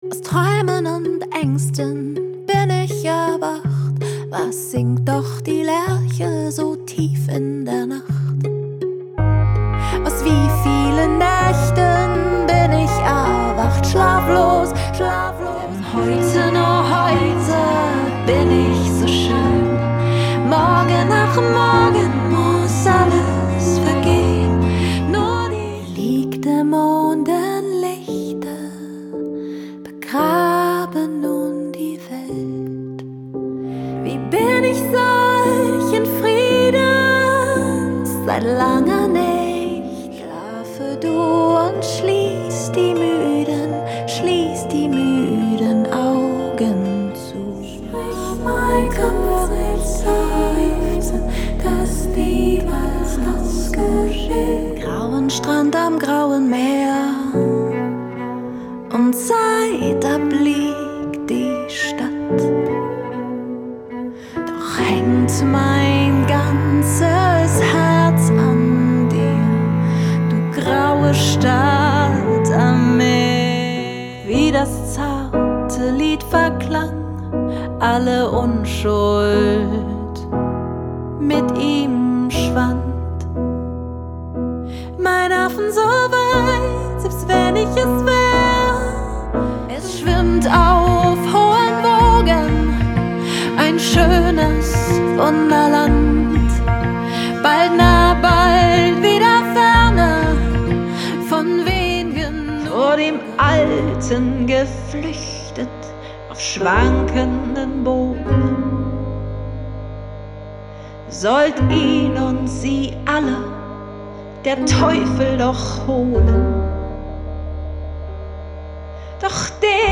Mit zarten und zugleich kraftvollen Farben